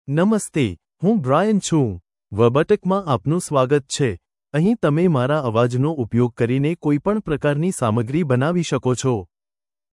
MaleGujarati (India)
Brian is a male AI voice for Gujarati (India).
Voice sample
Listen to Brian's male Gujarati voice.
Male